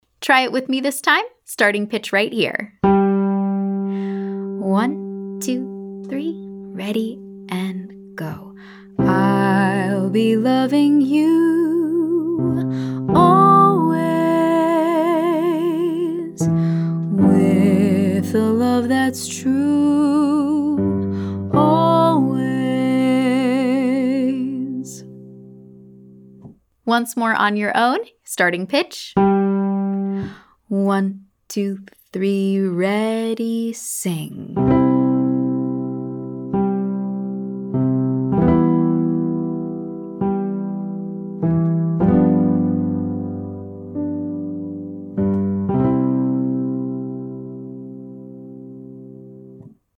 Day 13: Vibrato At The End Of A Sustained Pitch - Online Singing Lesson
Day 13: Vibrato At The End Of A Sustained Pitch
In addition to vibrato at the ends of phrases, you’ll also hear vibrato on sustained pitches.